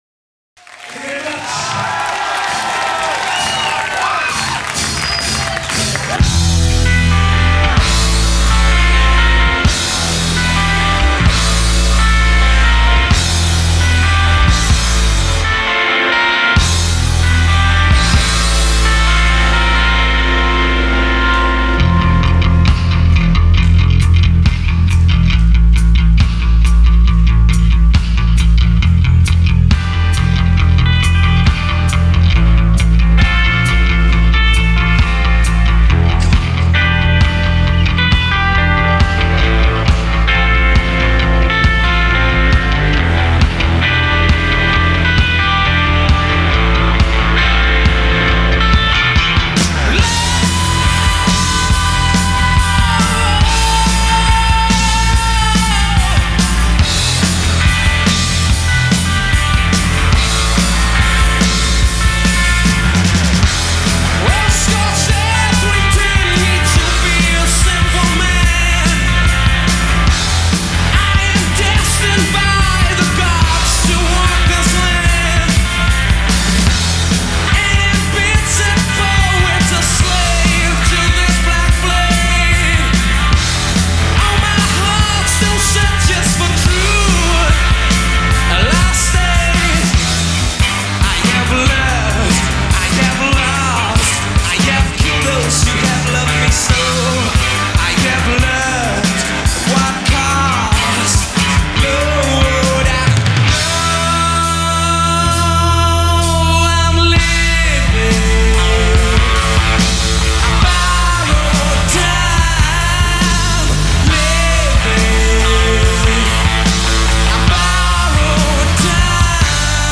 recorded at The Paris Theatre in London
Live at Paris Theatre, London
New Wave Heavy Metal